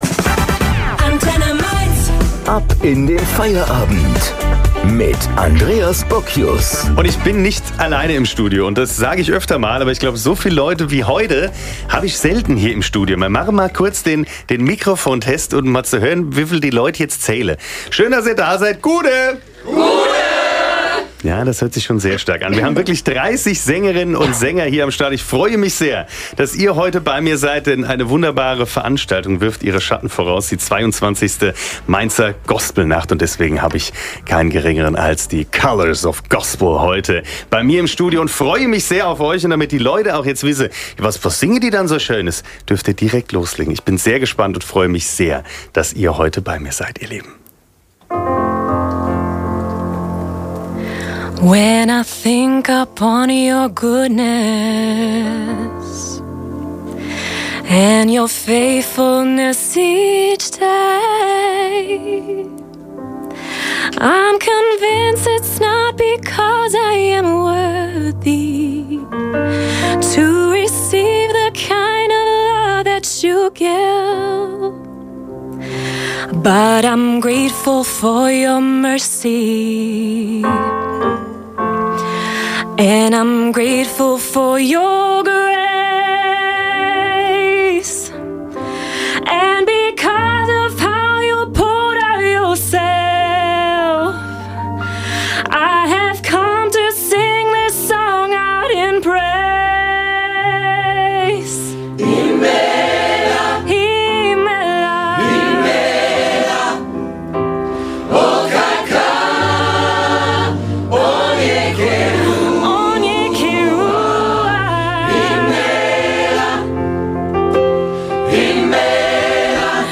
Der Powerchor aus Mainz.
Burgkirche Weihnachtsmarkt  Colours im Radio Wir waren am 1. Dezember im Radio bei Antenne Mainz zu Gast. Wer es live verpasst hat, findet hier einen Mitschnitt: Colours bei Antenne Mainz live 